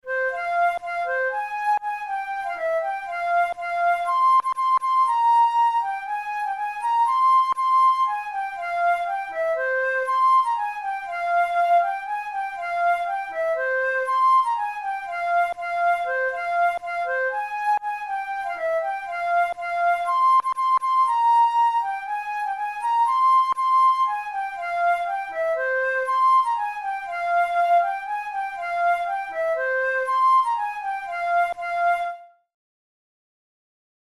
InstrumentationFlute solo
KeyF minor
Time signature4/4
Tempo120 BPM
Military music, Traditional/Folk, Wedding music